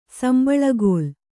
♪ sambaḷagōl